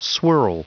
Prononciation du mot swirl en anglais (fichier audio)
Prononciation du mot : swirl